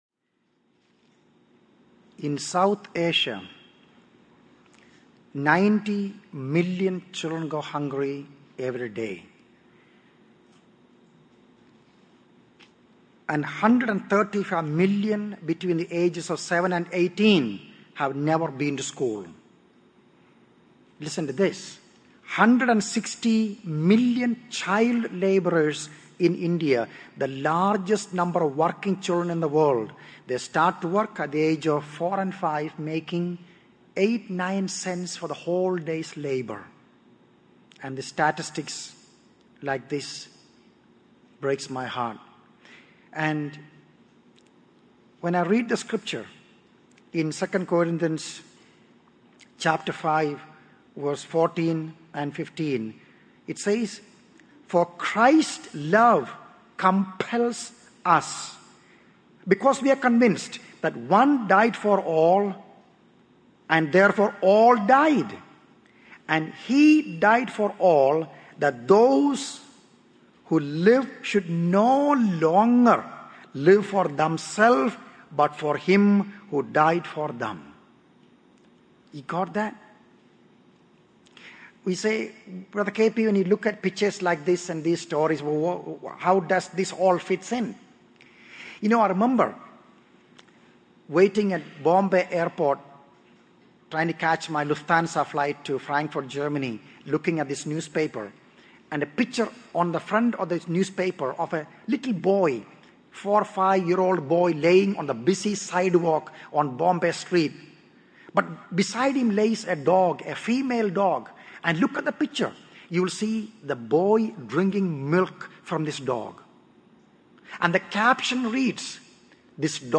In this sermon, the speaker reflects on his journey of detaching himself from materialism and worldly cares. He emphasizes the importance of living for Christ and evaluating every aspect of life in light of eternity and the lost world.